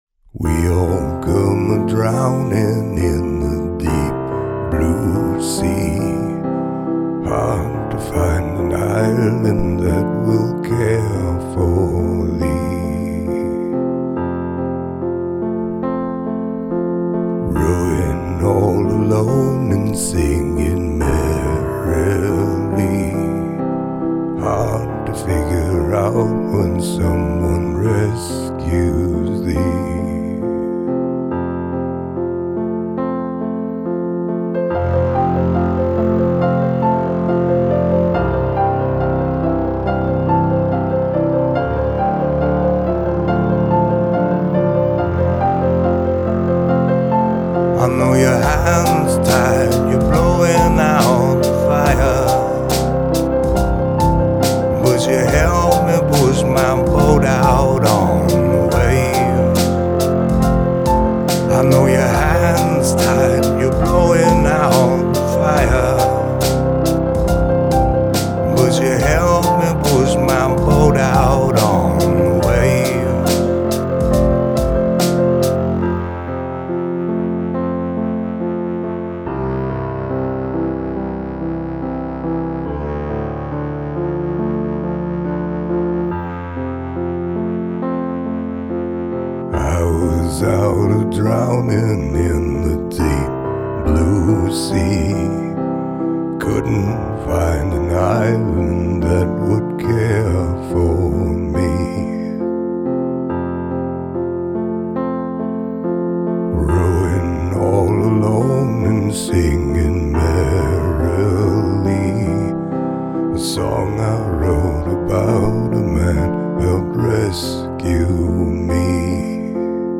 Fantastic song and truly amazing voice.